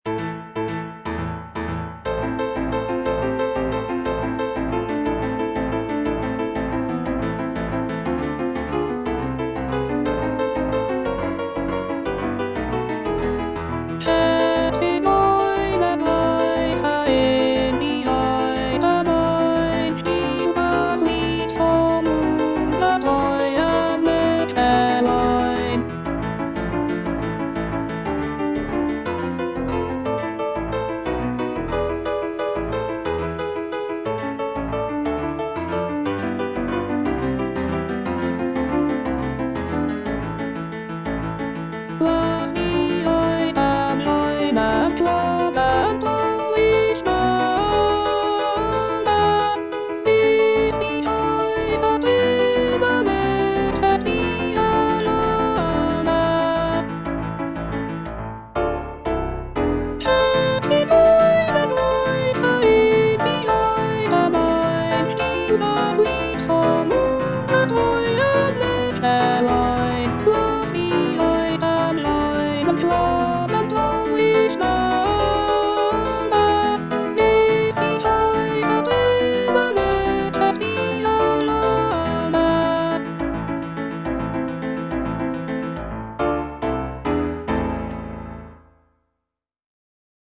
J. BRAHMS - HE, ZIGEUNER, GREIFE IN DIE SAITEN OP.103 NO.1 Alto (Voice with metronome) Ads stop: auto-stop
The piece is written in a lively and energetic manner, incorporating elements of Hungarian folk music.